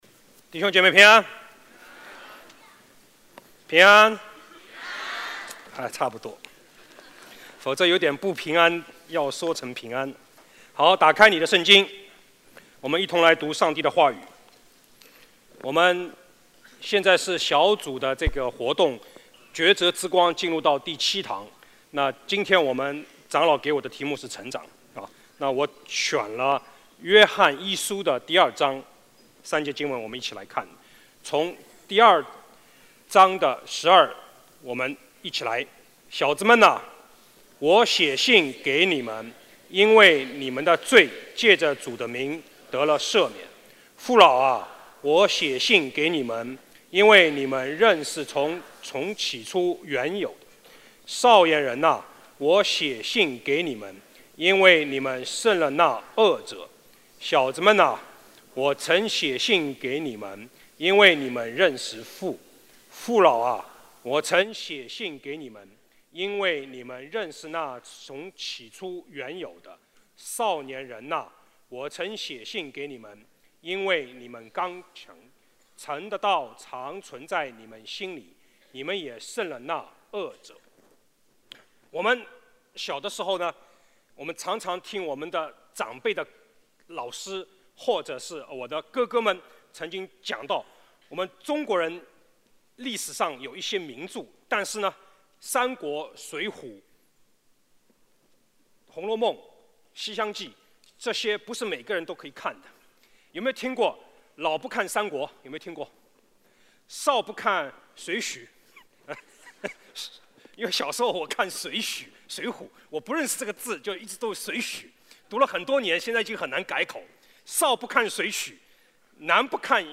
主日证道 | 成长